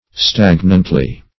stagnantly - definition of stagnantly - synonyms, pronunciation, spelling from Free Dictionary Search Result for " stagnantly" : The Collaborative International Dictionary of English v.0.48: Stagnantly \Stag"nant*ly\, adv. In a stagnant manner.
stagnantly.mp3